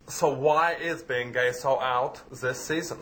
Bruno Movie Sound Bites